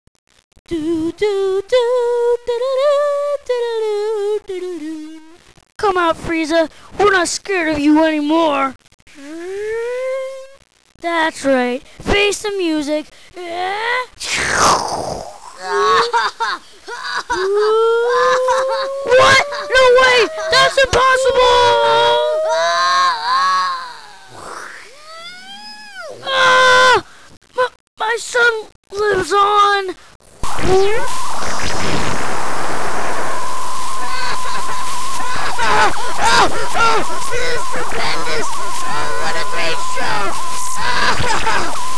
Oh, and don't mail me saying that the scripts are not exact, they're not meant to be.